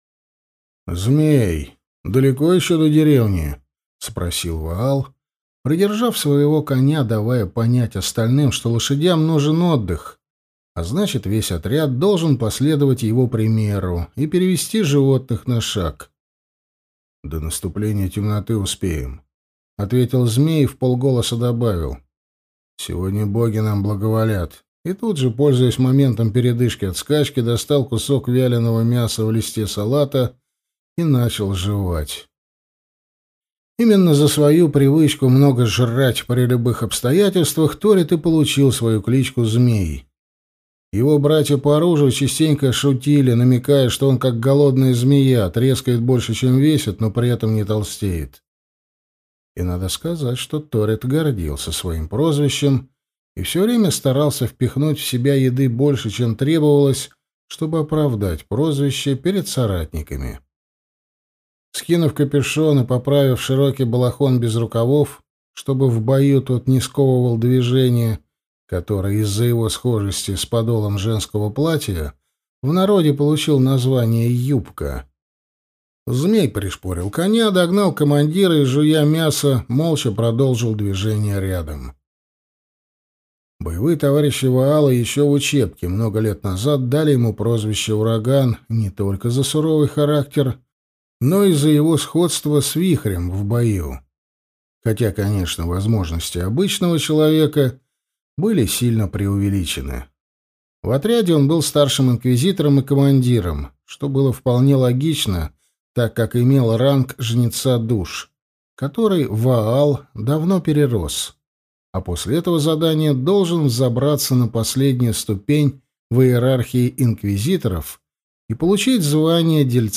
Аудиокнига Хранящий память. Инквизитор | Библиотека аудиокниг